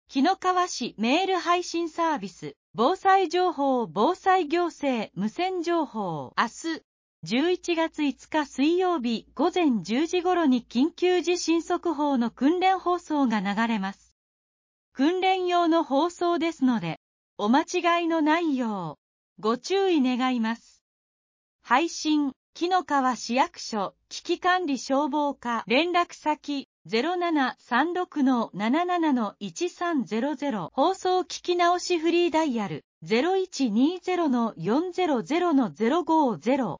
明日、１１月５日（水）午前１０時頃に緊急地震速報の訓練放送が流れます。
紀の川市メール配信サービス 【防災情報・防災行政無線情報】 明日、１１月５日（水）午前１０時頃に緊急地震速報の訓練放送が流れます。